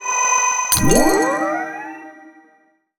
spell_recharge_poweup_01.wav